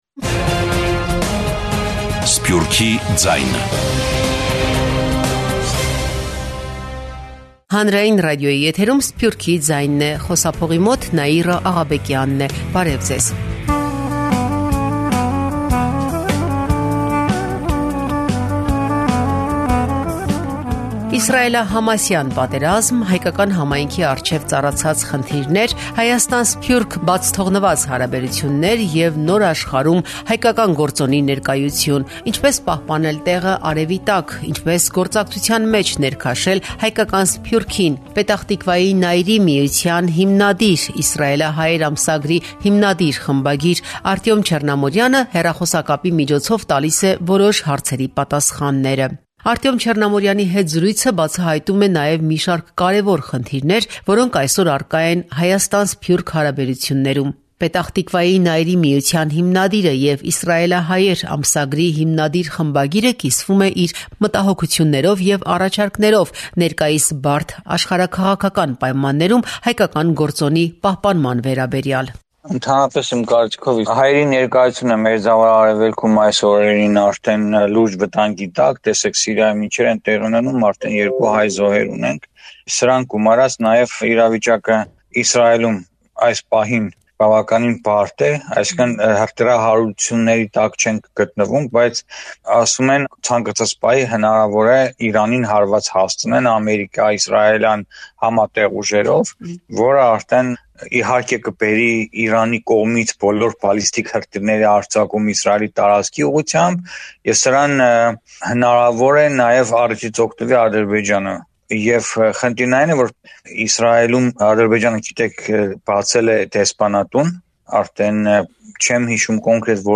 հեռախոսակապի միջոցով կիսվում է իր տեսլականով և առաջարկում գործնական լուծումներ